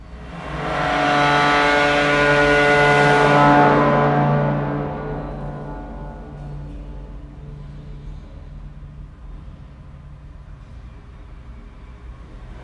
描述：在Baschet Sound Sculpture中演奏弓，木和金属的声音